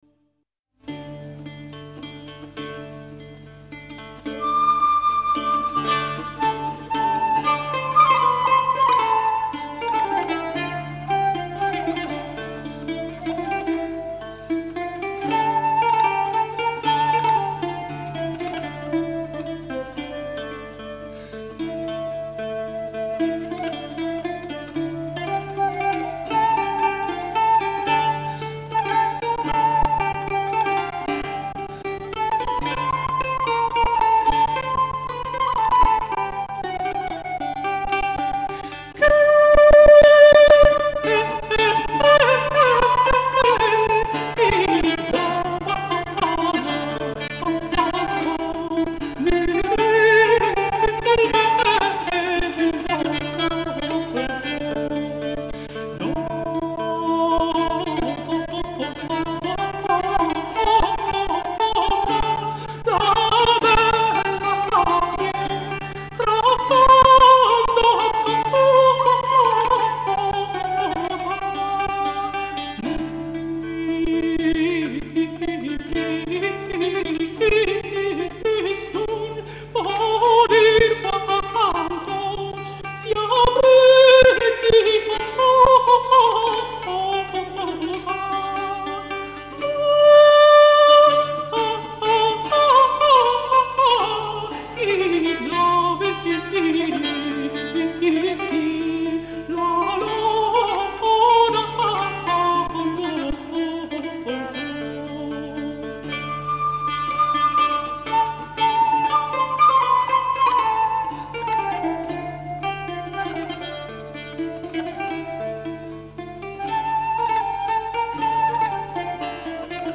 che si dedica alla ricerca nel campo delle musiche medioevali, rinascimentali e barocche, usando ricostruzioni di strumenti dell’epoca.